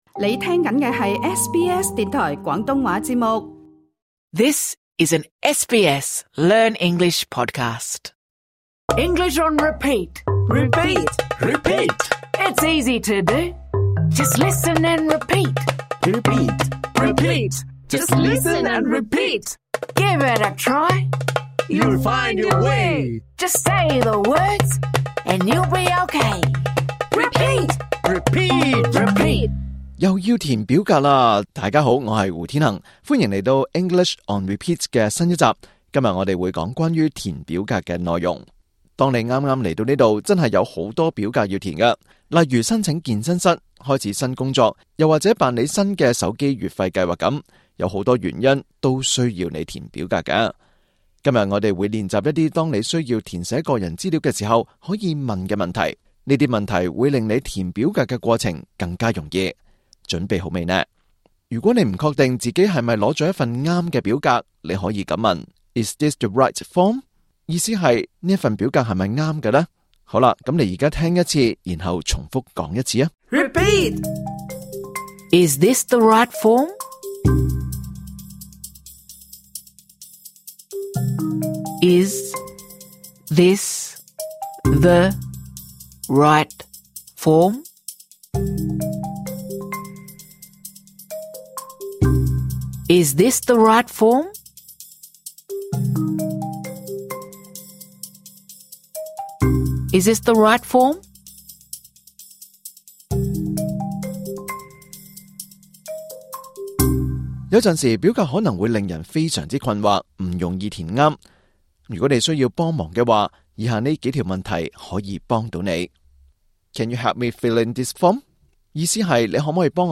今集為初學者設計的簡單課程。